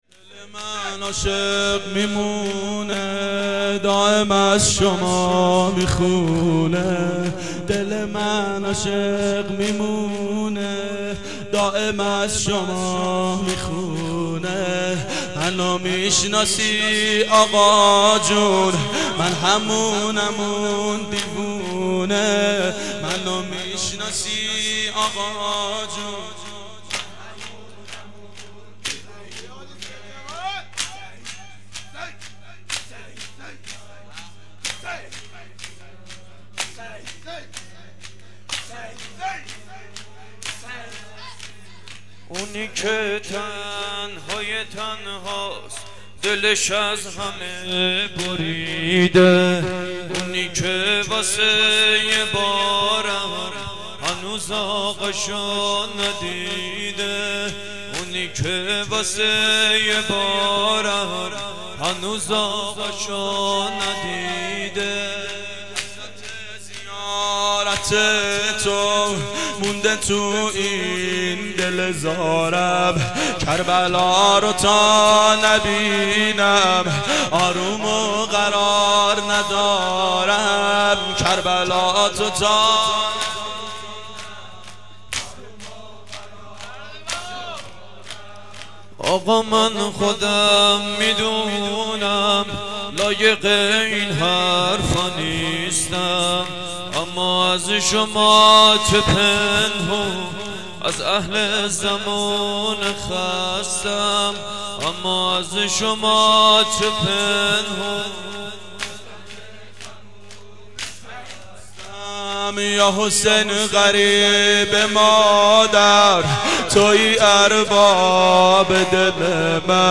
شب سوم محرم 89 گلزار شهدای شهر اژیه